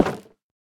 Minecraft Version Minecraft Version 1.21.5 Latest Release | Latest Snapshot 1.21.5 / assets / minecraft / sounds / block / nether_wood_fence / toggle1.ogg Compare With Compare With Latest Release | Latest Snapshot